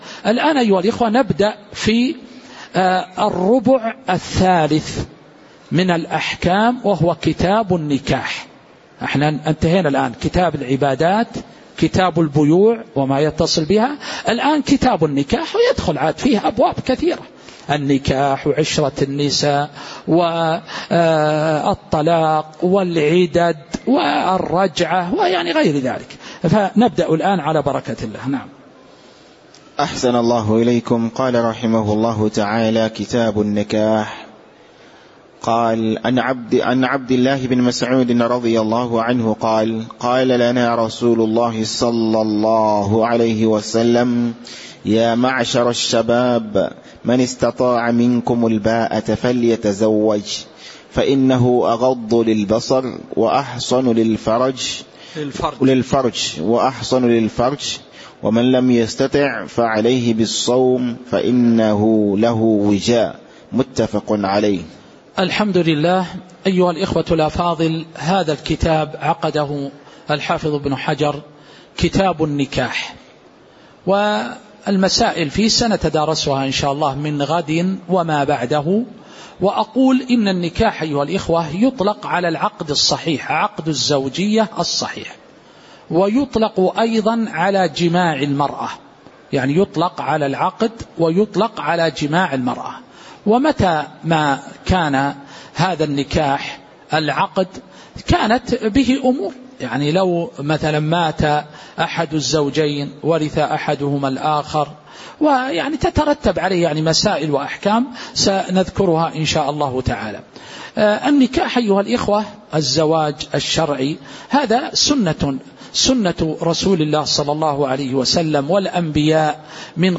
تاريخ النشر ٢ شعبان ١٤٤٦ هـ المكان: المسجد النبوي الشيخ